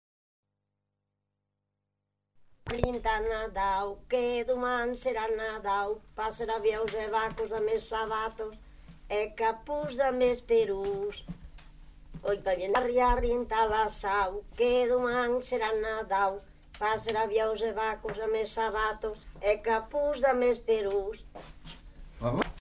Aire culturelle : Savès
Lieu : Puylausic
Genre : forme brève
Effectif : 1
Type de voix : voix de femme
Production du son : chanté
Classification : formulette enfantine